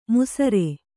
♪ musare